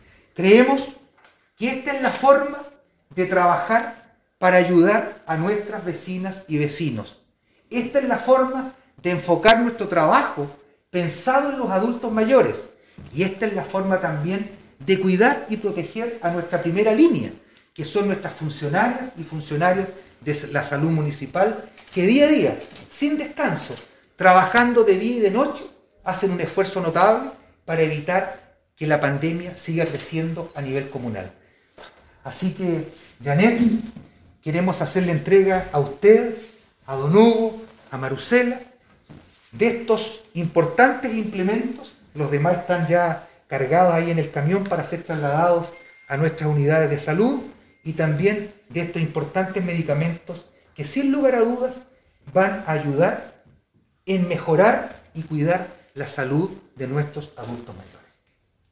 En una ceremonia simbólica el alcalde de la comuna de Castro, Juan Eduardo Vera, hizo entrega de kit de medicamentos e insumos de protección personal a la Red de Salud Municipal de la comuna en apoyo a sus funcionarios, en estos momentos de crisis provocada por el Covid-19.
alcalde-entrega-de-medicamentos1.mp3